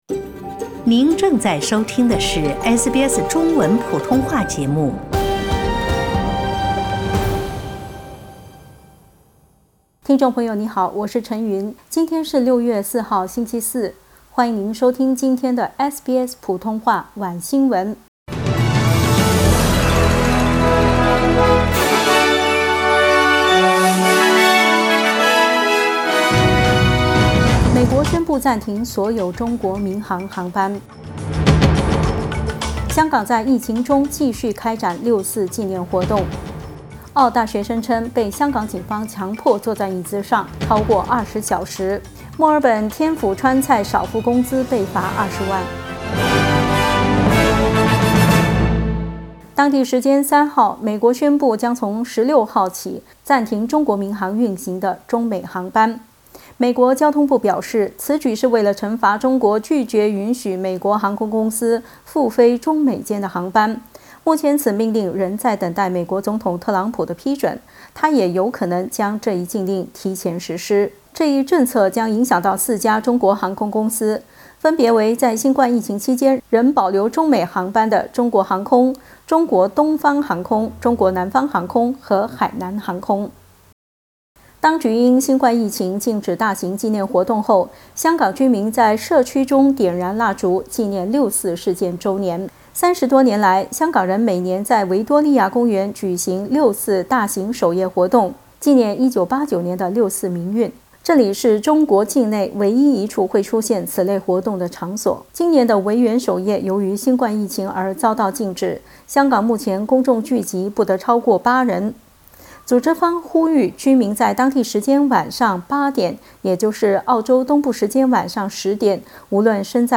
SBS晚新闻（6月4日）